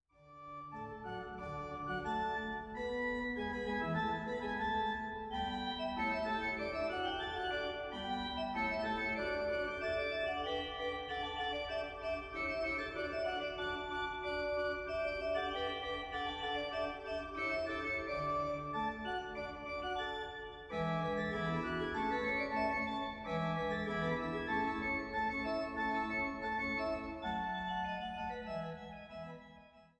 älteste Orgel im Kreis Düren